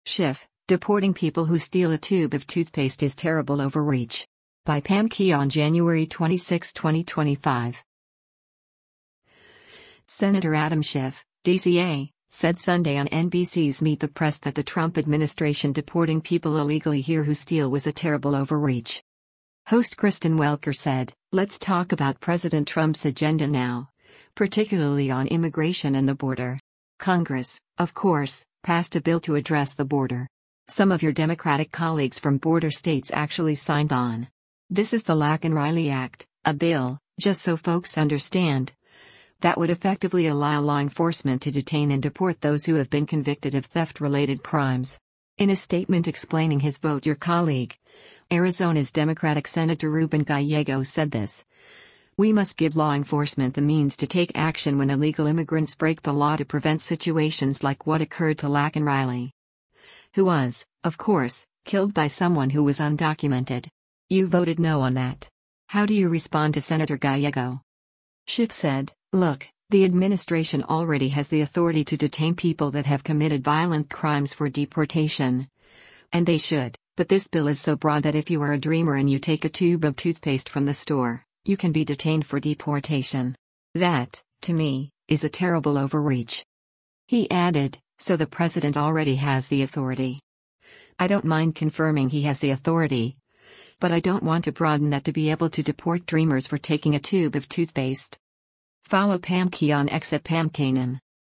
Senator Adam Schiff (D-CA) said Sunday on NBC’s “Meet the Press” that the Trump administration deporting people illegally here who steal was a “terrible overreach.”